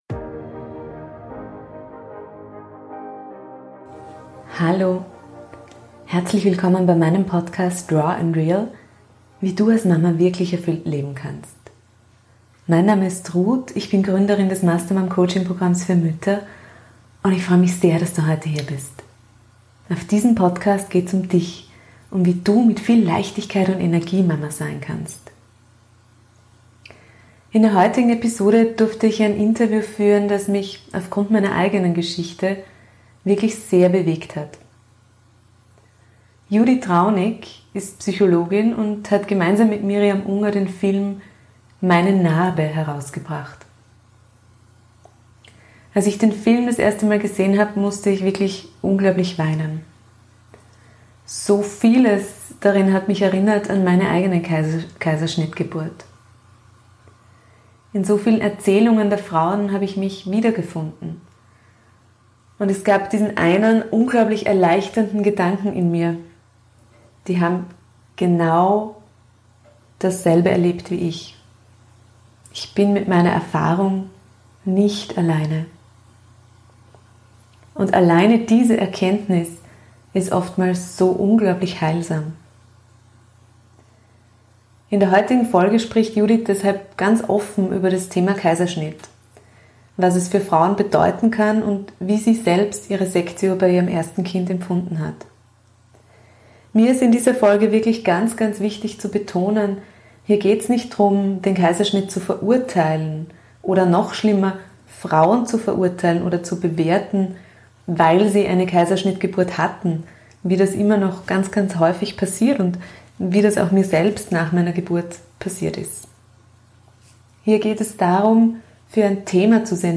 Ein sehr bewegendes Interview, indem es nicht darum geht, den Kaiserschnitt zu verurteilen, sondern Sensibilität für ein immer noch tabuisiertes Thema zu schaffen.